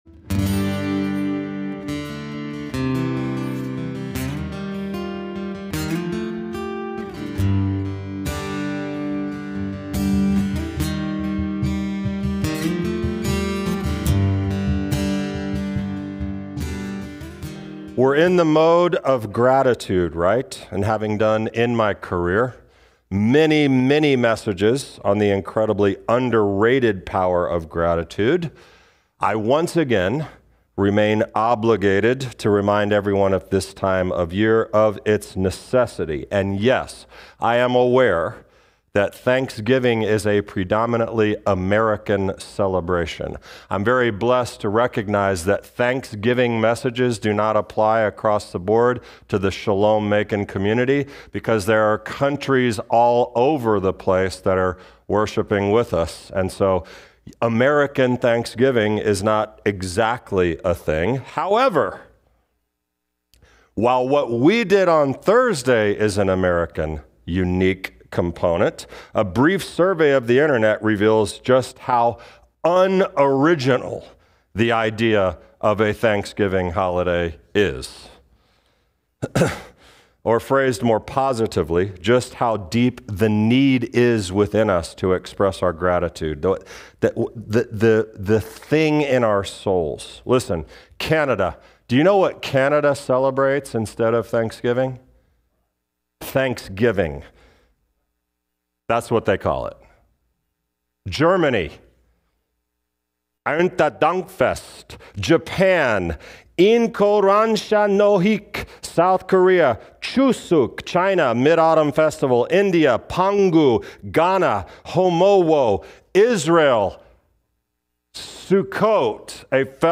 Shalom Macon Messianic Jewish Teachings Podcast-uri